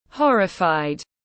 Horrified /’hɒrɪfaɪ/